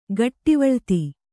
♪ gaṭṭivaḷti